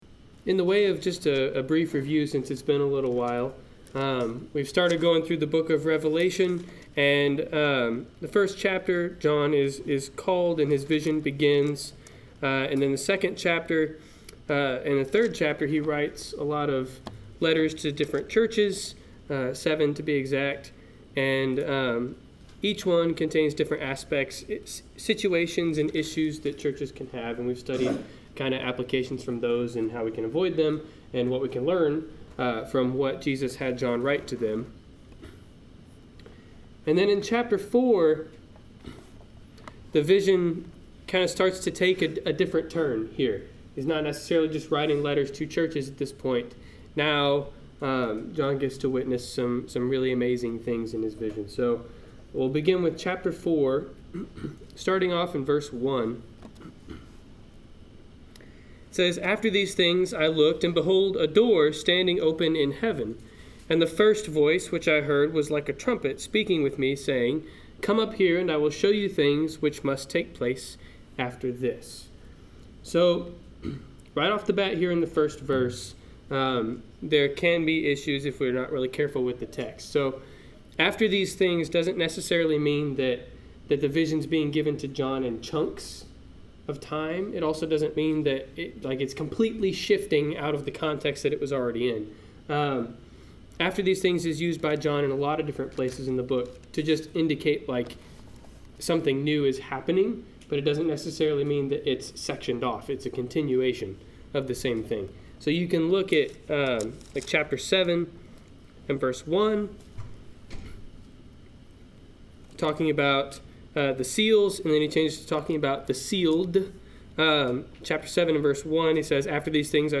Service Type: Wednesday Night Class